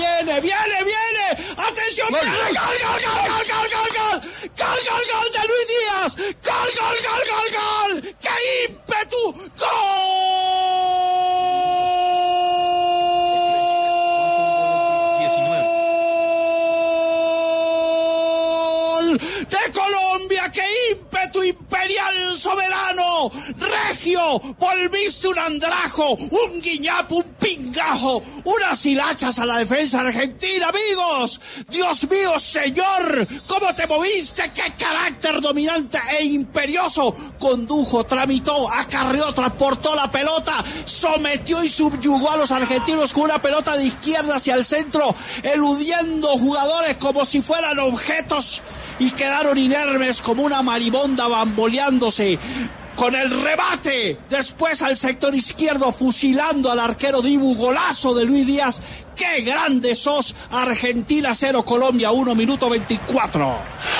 “¡Que ímpetu imperial": increíble narración de Martin De Francisco del golazo de Luis Díaz
Escuche la narración de Marín De Francisco en toda su esencia al gol de la Selección Colombia ante Argentina.